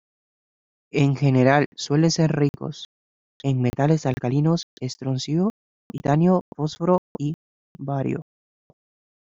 Pronounced as (IPA)
/ˈfosfoɾo/